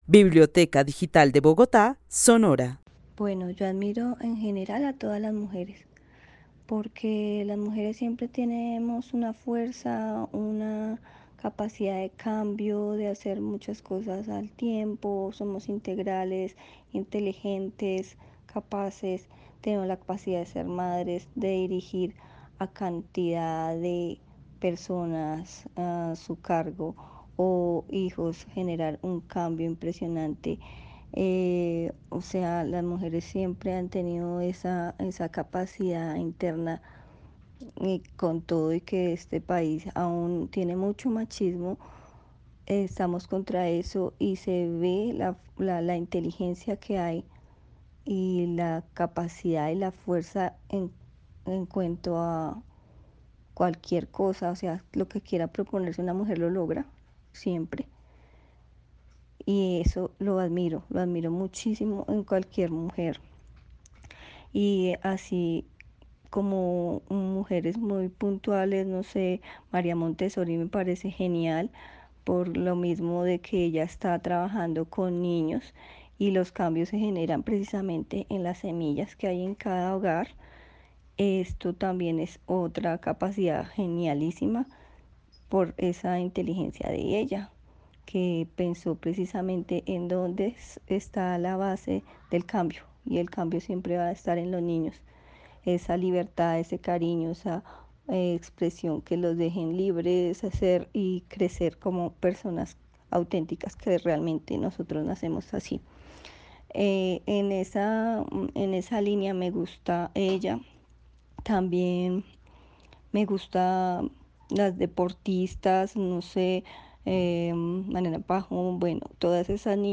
Narración oral de una mujer que vive en la ciudad de Bogotá quien admira a todas las mujeres porque todas tienen fuerza y capacidad de cambio, de hacer muchas cosas a la vez.
El testimonio fue recolectado en el marco del laboratorio de co-creación "Postales sonoras: mujeres escuchando mujeres" de la línea Cultura Digital e Innovación de la Red Distrital de Bibliotecas Públicas de Bogotá - BibloRed.